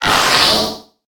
Cri de Fourbelin dans Pokémon HOME.